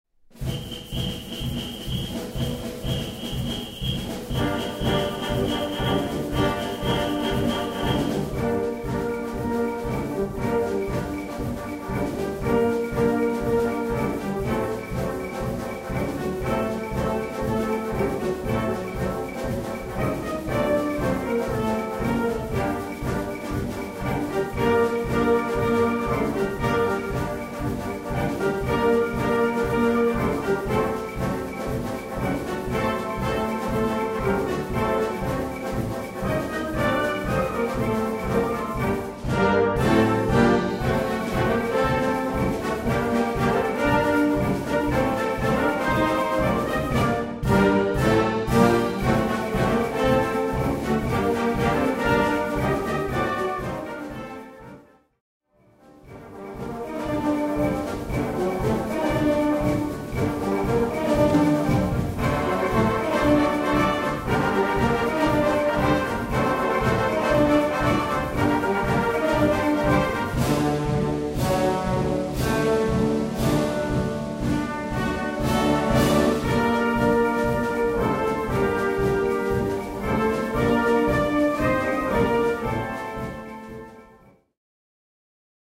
Konzertante Unterhaltungsmusik
Blasorchester